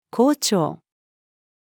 紅潮-female.mp3